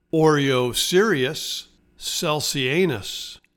Pronounciation:
Or-ee-oo-SEAR-ee-us cell-see-A-nus